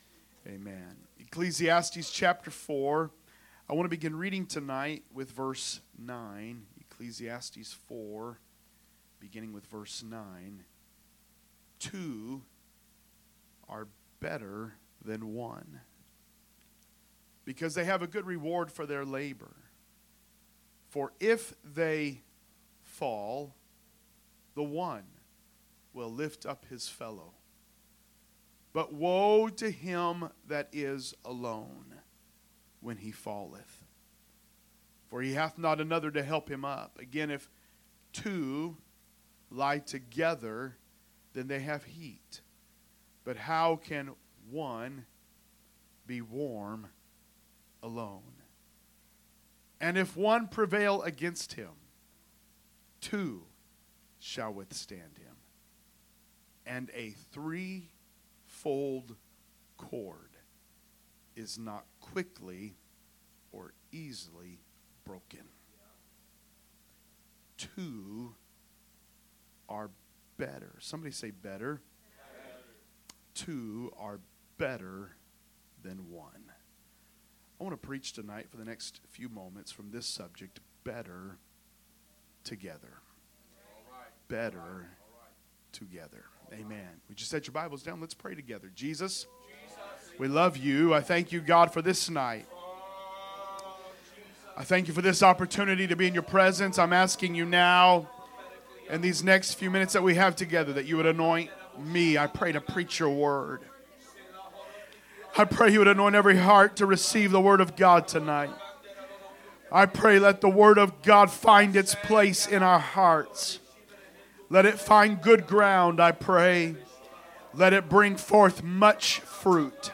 A message from the series "Guest Speakers." Wednesday Message